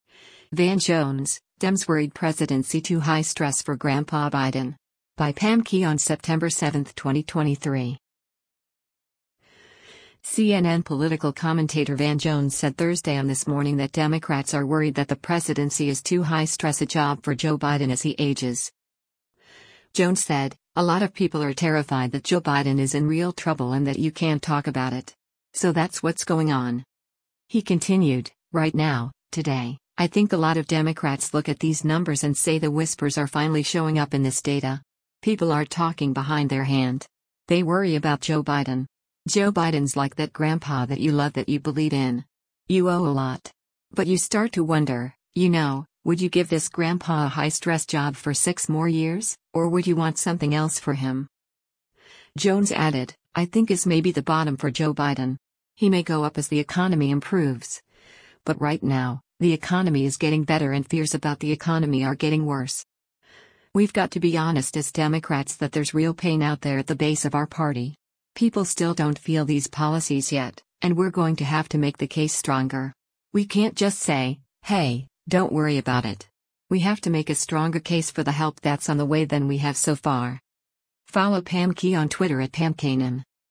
CNN political commentator Van Jones said Thursday on “This Morning” that Democrats are worried that the presidency is too “high-stress” a job for Joe Biden as he ages.